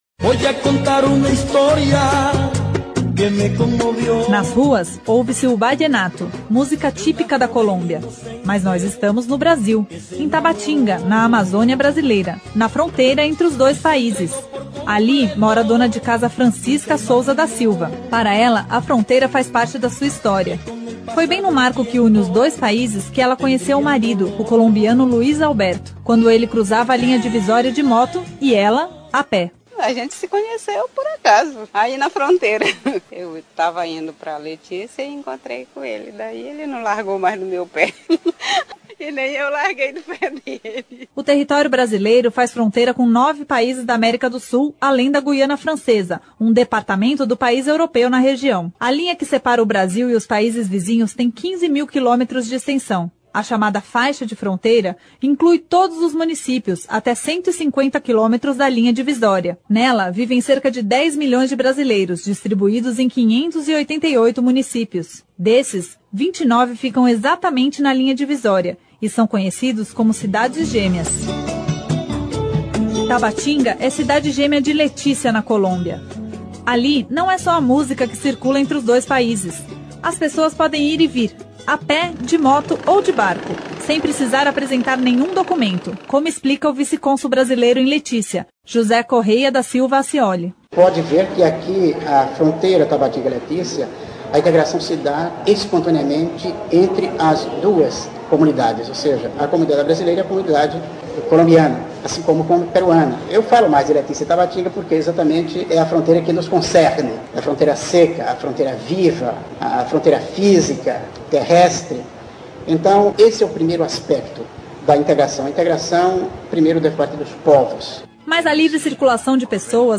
Reportagem especial Cidadania nas Fronteiras conta como é a vida dos cerca de 10 milhões de brasileiros que moram nas divisas do país